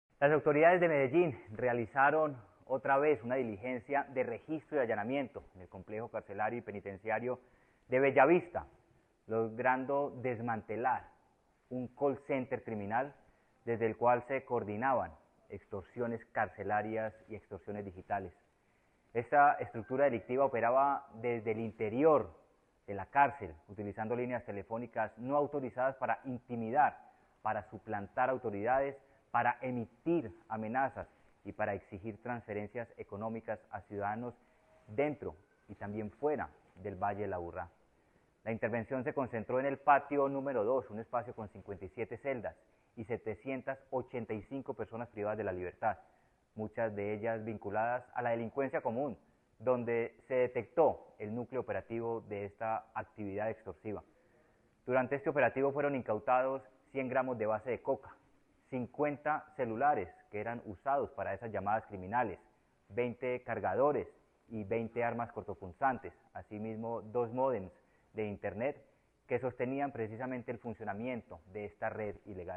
Declaraciones secretario de Seguridad y Convivencia, Manuel Villa Mejía
Declaraciones-secretario-de-Seguridad-y-Convivencia-Manuel-Villa-Mejia.mp3